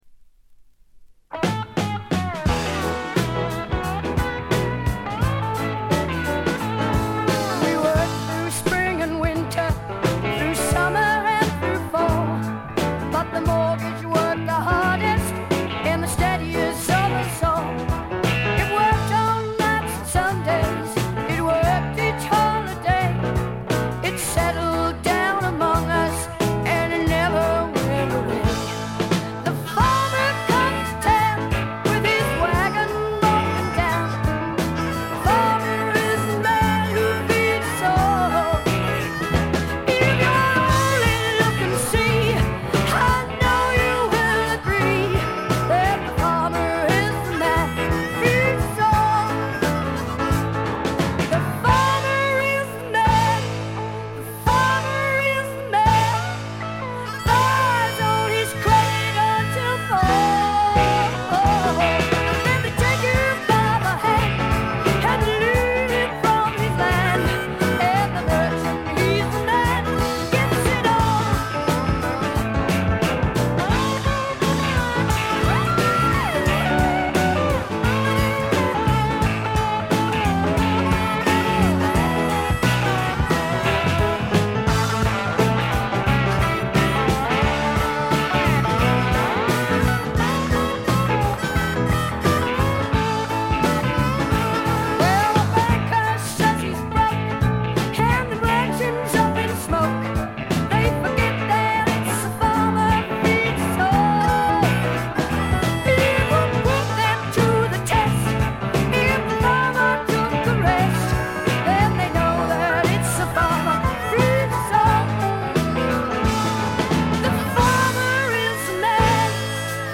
ホーム > レコード：英国 スワンプ
録音はロンドンとナッシュビルで録り分けています。
試聴曲は現品からの取り込み音源です。